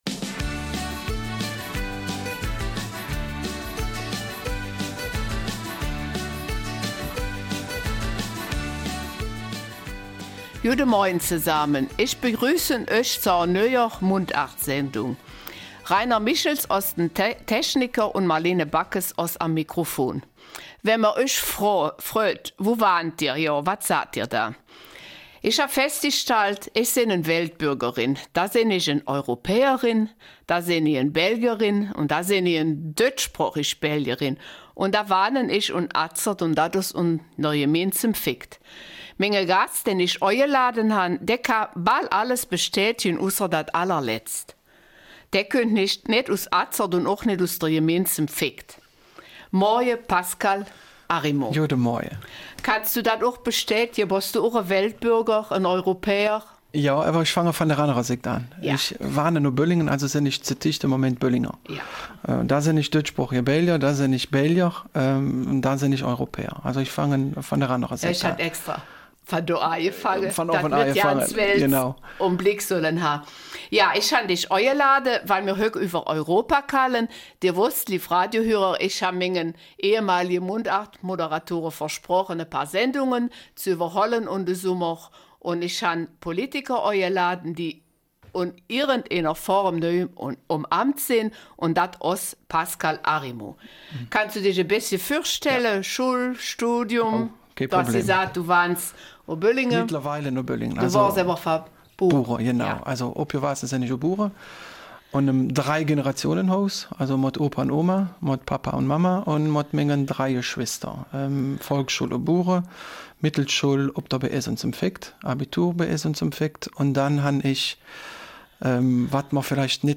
Eifeler Mundart - 7. August
Pascal Arimont, Jahrgang 1974, ist seit den letzten Wahlen EU-Abgeordneter. Im Gespräch geht es zuerst um einige Zahlen: Die EU besteht (noch) aus 28 Nationen, hat 507 Millionen Einwohner, es gibt 751 Abgeordnete in acht politischen Fraktionen und einer neutralen Gruppe.